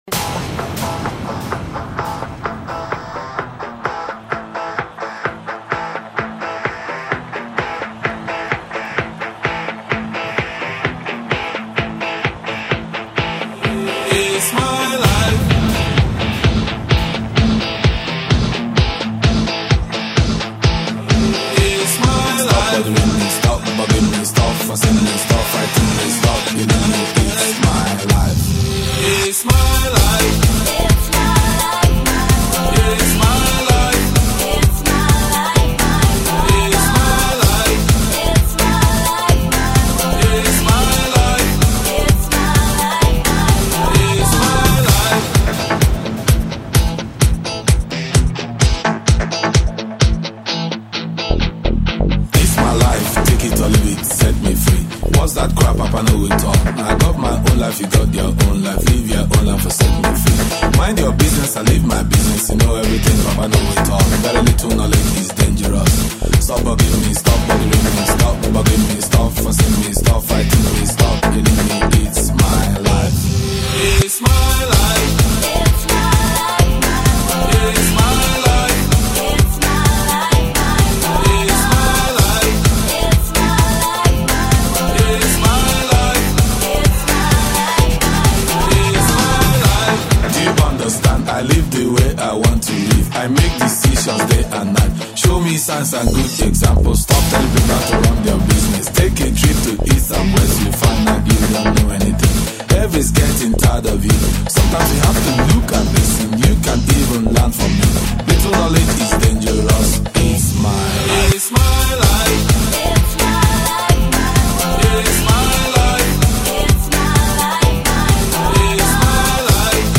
mix) - совсем даже не плохой клубняк wink